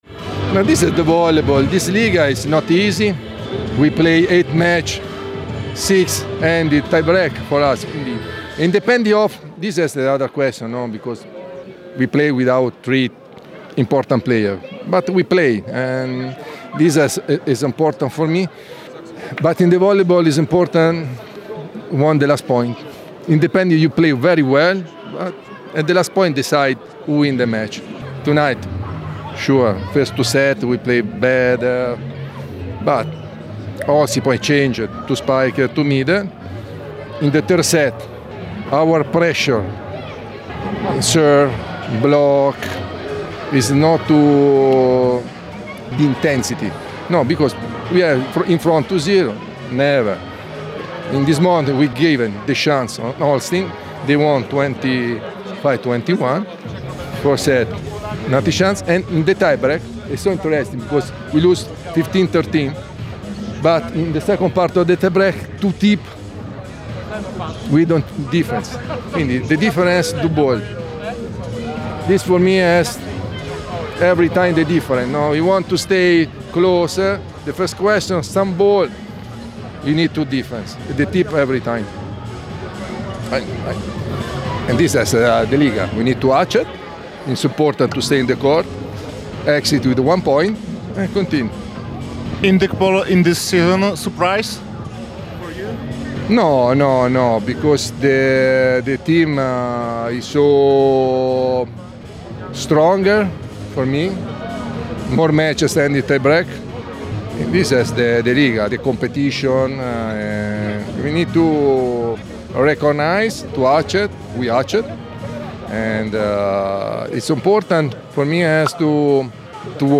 – mówił włoski szkoleniowiec.